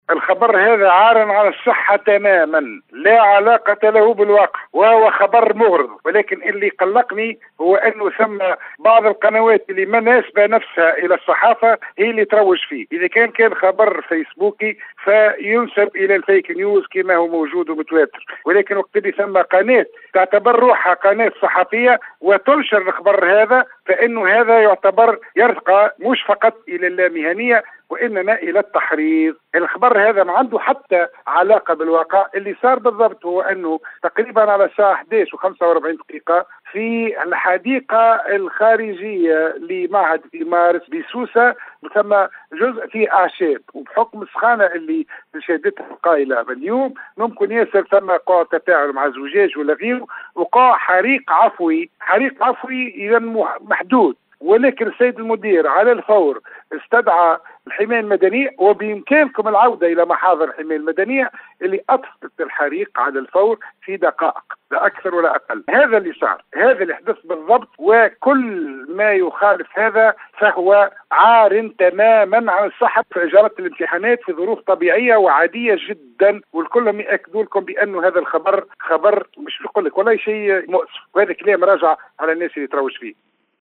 نفى نجيب الزبيدي المدير الجهوي للتربية بسوسة منذ قليل في تصريح  لإذاعة RM FM ما يتم تداوله في بعض مواقع التواصل الإجتماعي و المنصات الإعلامية من أخبار حول عمد مجموعة من التلاميذ حرق قاعة الأساتذة بمعهد 2 مارس بسوسة مؤكدا على الإمتحانات جرت اليوم بالمعهد المذكور في ظروف طيبة و عادية.